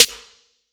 Snare SwaggedOut 6.wav